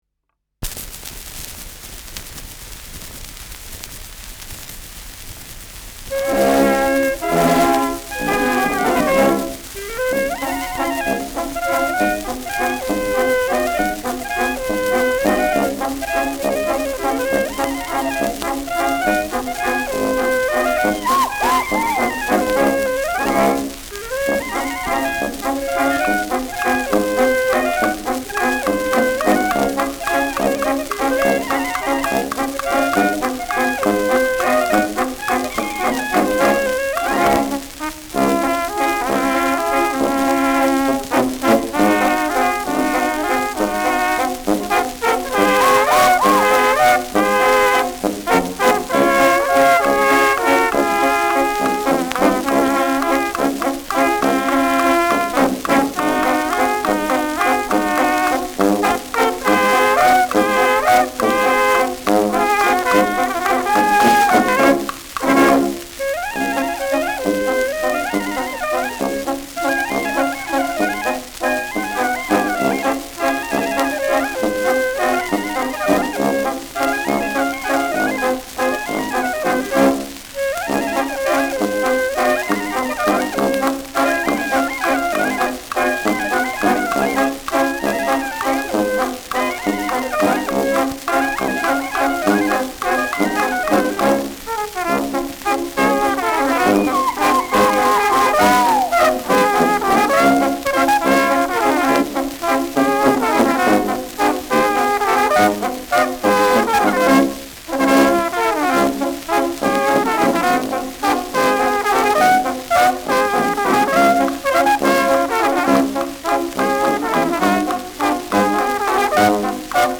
Schellackplatte
präsentes Rauschen : geringes Leiern
Mit Juchzern und Klopfgeräuschen.